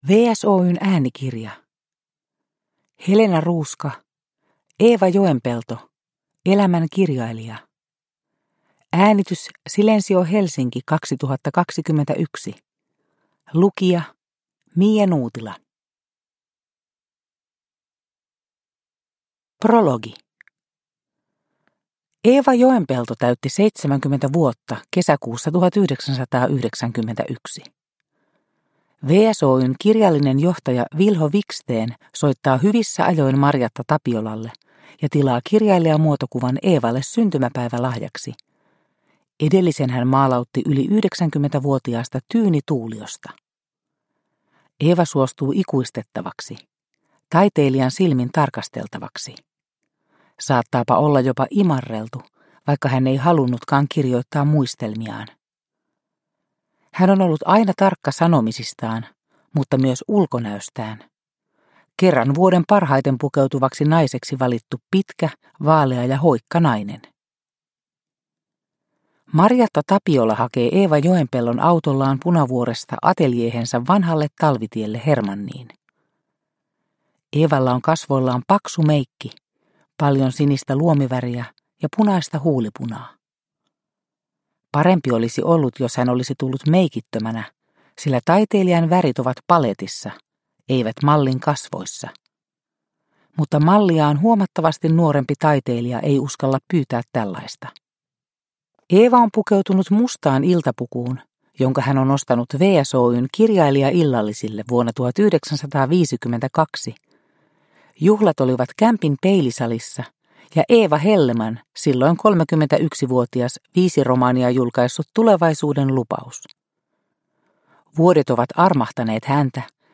Eeva Joenpelto. Elämän kirjailija – Ljudbok – Laddas ner